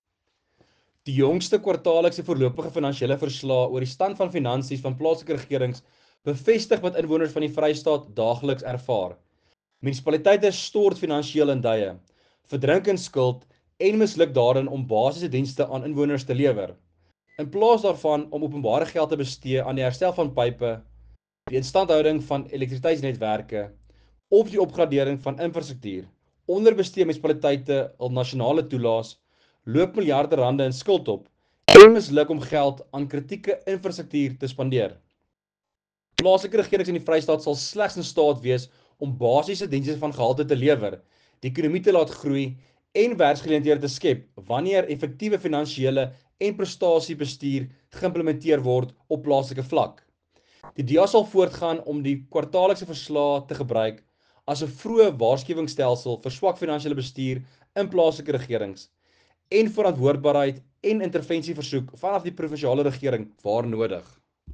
Afrikaans soundbites by Werner Pretorius MPL and